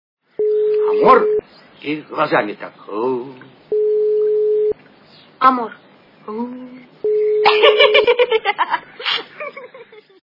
» Звуки » Из фильмов и телепередач » Формула любви - Амор... и глазами так Ууу